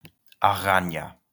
2. ^ /əˈɡɑːnjə/ ə-GAH-nyə, Spanish pronunciation: [aˈɣaɲa]